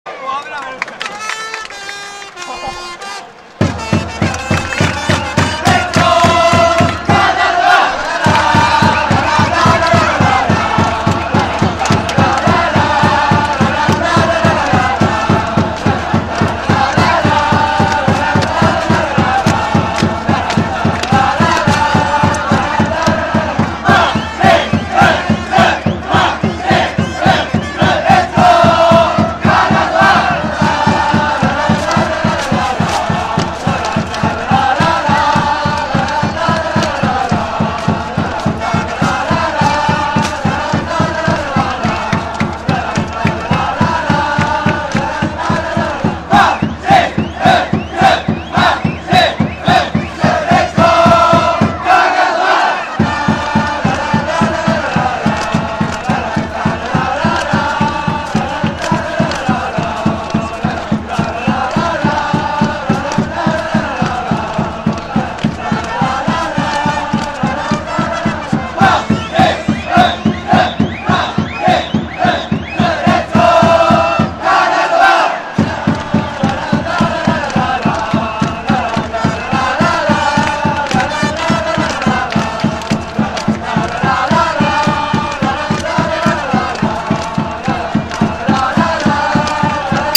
６２ 金沢岳 H18 7/20 東Ｄ 応援歌 何と神の歌を流用。